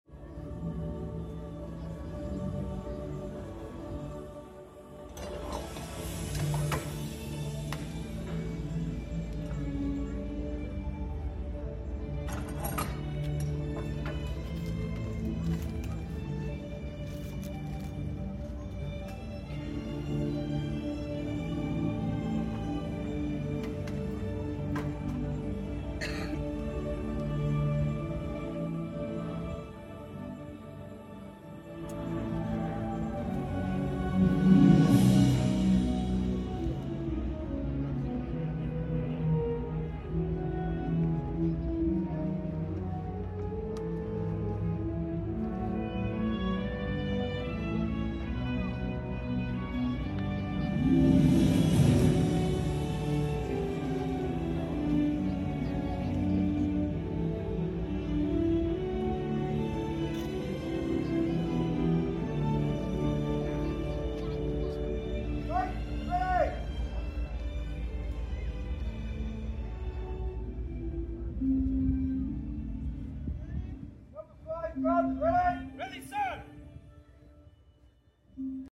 History just got loud in Malta 💥 Ever heard a real cannon fire? This is the Saluting Battery in Valletta — and yes, it’s as epic as it sounds.
🔥 Loud, dramatic, and seriously cool to watch Malta doesn’t just show history — it fires it.